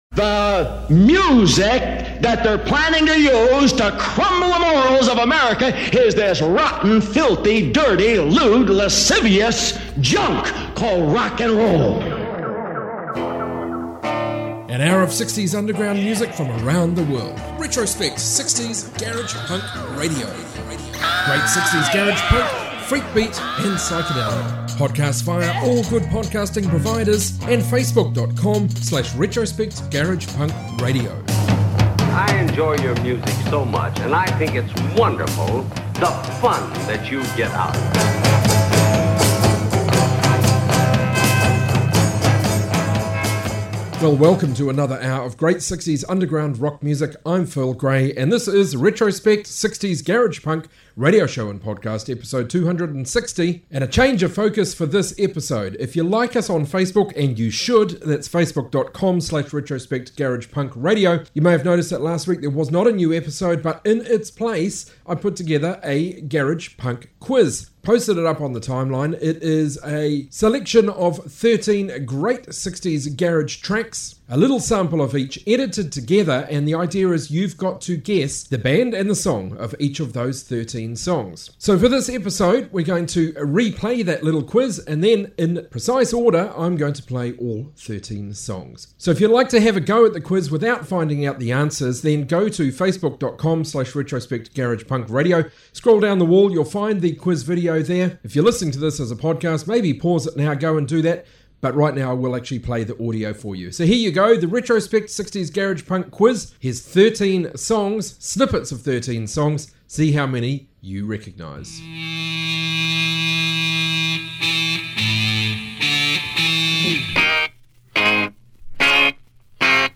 60s garage rock garage punk freakbeat